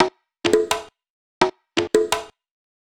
Percussion 14.wav